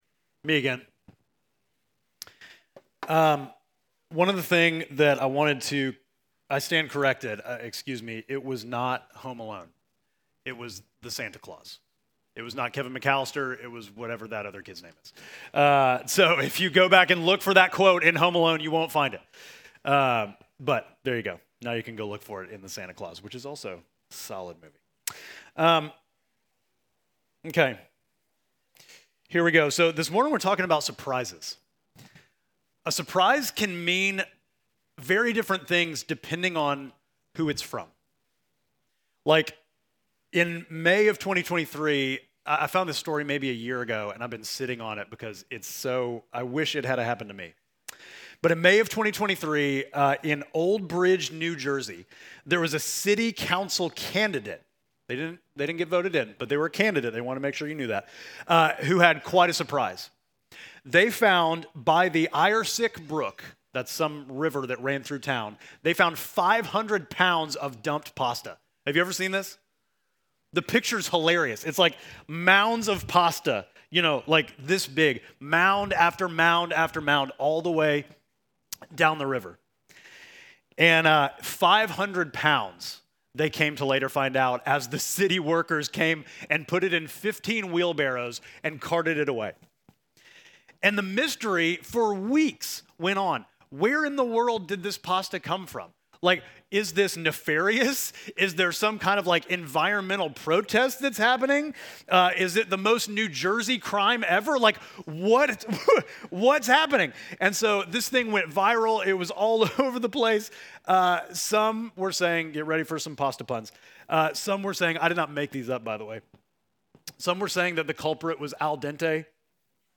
Midtown Fellowship Crieve Hall Sermons You Have Found Favor With God Dec 14 2025 | 00:35:28 1x 00:00 / 00:35:28 Subscribe Share Apple Podcasts Spotify Overcast RSS Feed Share Link Embed